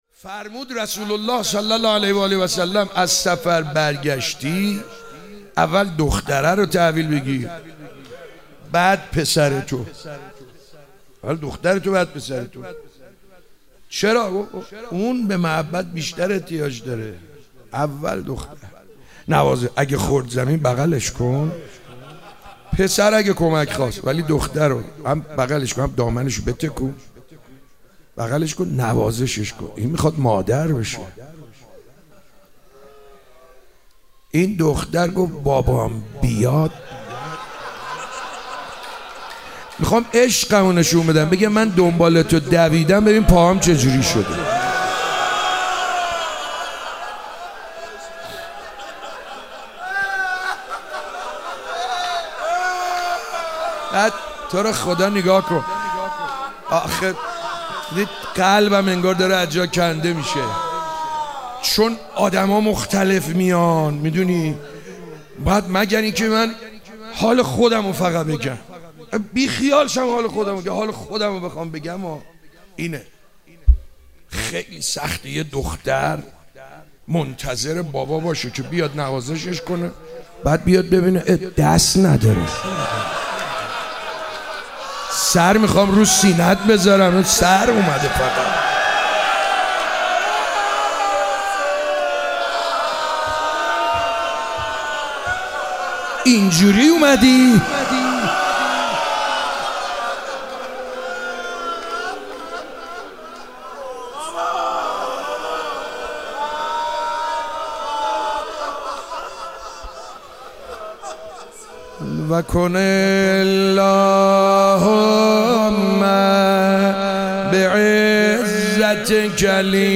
روضه - حضرت رقیه سلام الله علیها